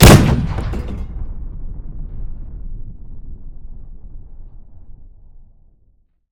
tank-cannon-1.ogg